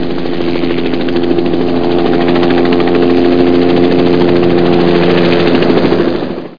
Amiga 8-bit Sampled Voice
1 channel
AirStrike.mp3